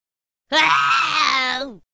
firey screaming Meme Sound Effect
firey screaming.mp3